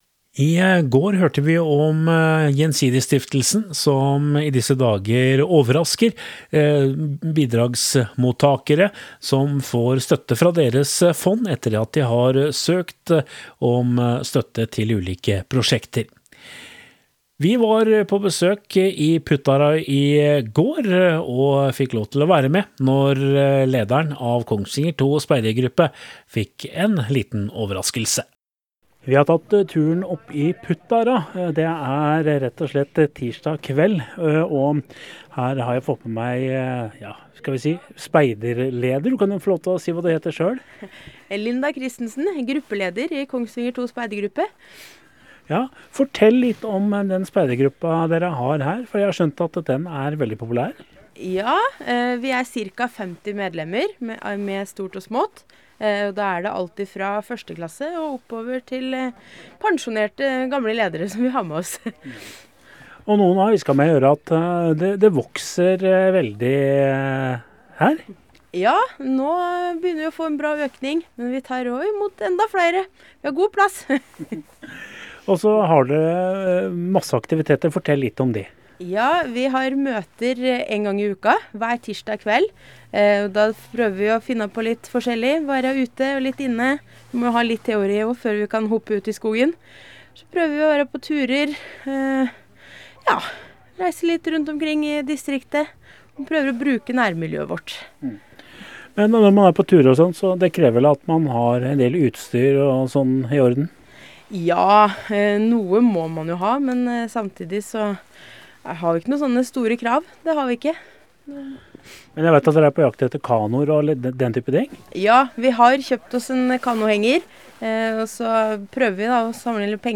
Tirsdag kveld fikk Kongsvinger 2 Speidergruppe en gledelig overraskelse under et vanlig speidermøte i Puttara.